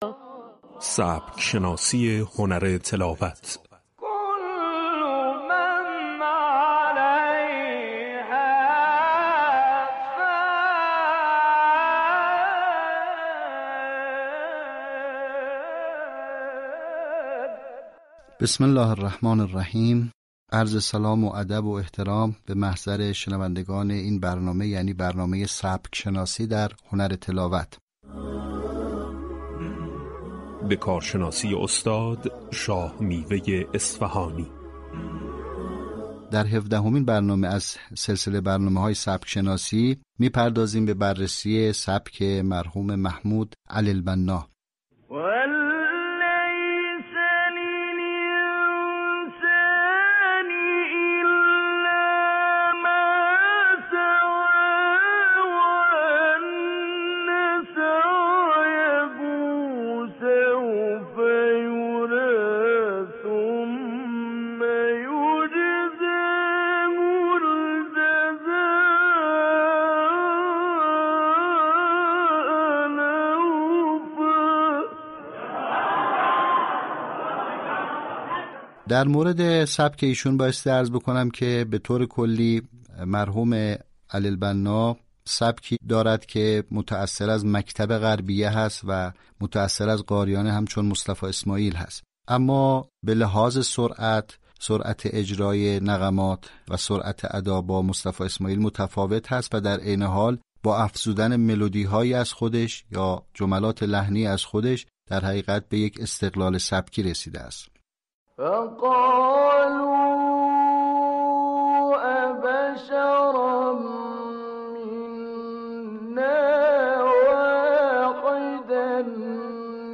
آموزش سبک شناسی در هنر تلاوت قرآن